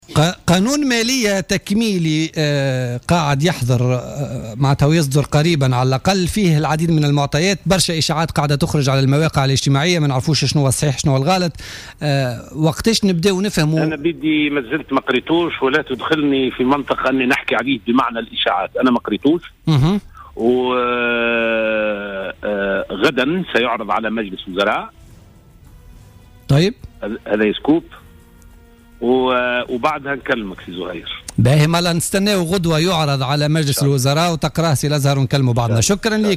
قال الوزير المكلف لدى رئيس الحكومة بالعلاقات مع مجلس نواب الشعب،لزهر العكرمي في تصريح ل"جوهرة أف أم" إنه من المنتظر عرض قانون المالية التكميلي على مجلس الوزراء غدا الإربعاء 29 جويلية 2015.